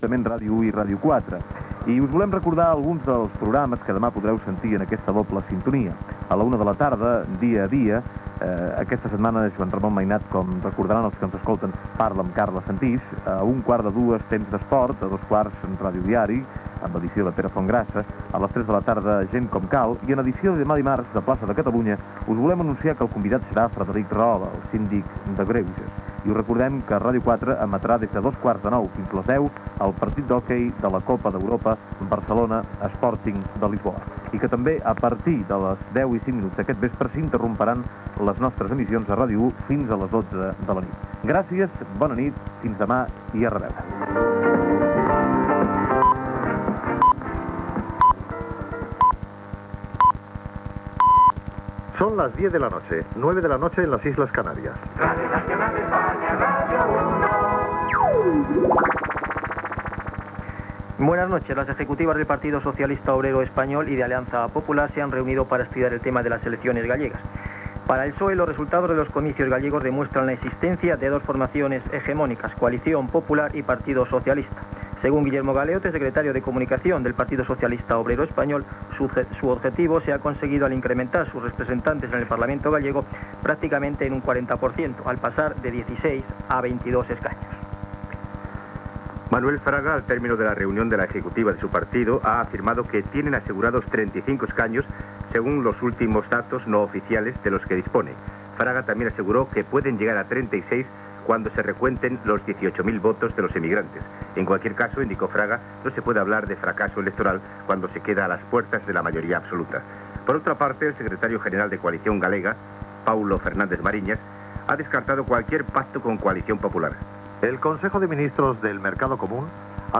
Notícies: resultat de les eleccions gallegues, consell de ministres del mercat comú, inflació dels preus, atemptat d'ETA, detenció d'un membre d'ETA a França, segrest d'un avió, atemptat a Frankfurt, etc. Identificació de l'emissora i avís de la suspensió de l'emissió per un reajustament tècnic al centre emissor de Palau de Plegamans. Sintonia de l'emissora.
Informatiu